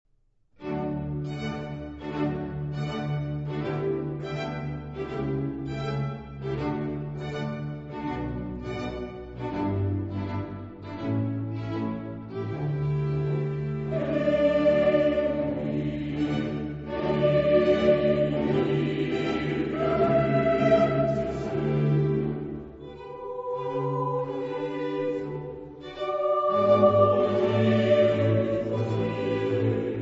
Corale
direttore d'orchestra
• Registrazione sonora musicale